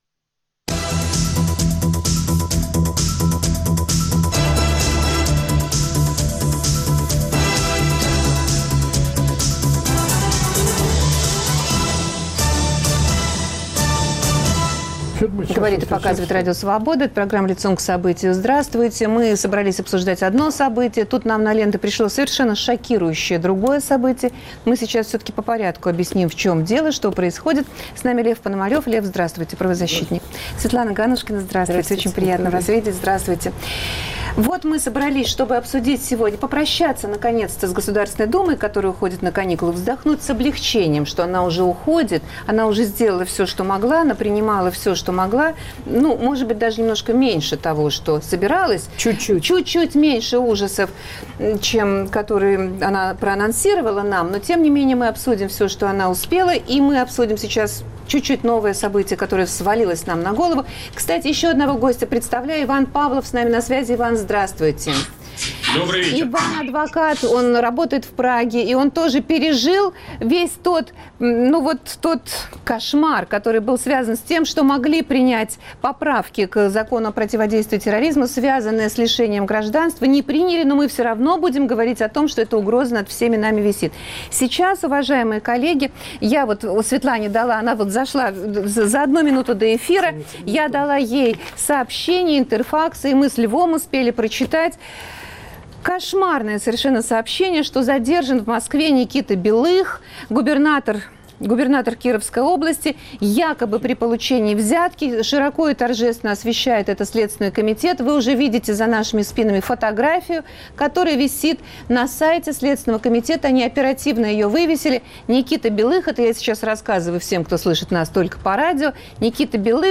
Думские поправки в закон о противодействии терроризму признаны беспрецедентно жестокими. Против кого они нацелены на самом деле? В гостях правозащитники Светлана Ганнушкина и Лев Пономарев